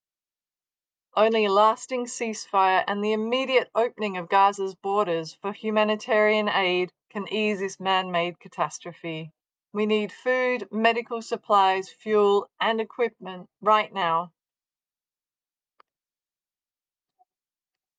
Voicenotes
recorded Monday 2nd June at Nasser Hospital, Gaza